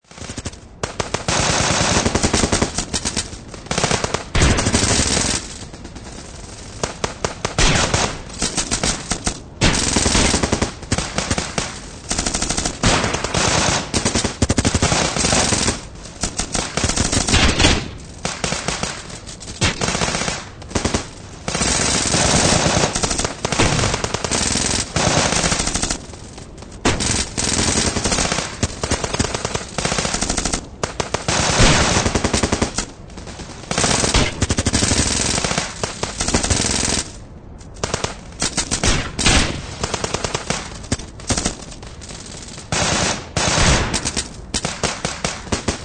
Nhạc Chuông Tiếng Súng Đạn Chiến Tranh